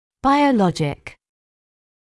[ˌbaɪəu’lɔʤɪk][ˌбайоу’лоджик]биологический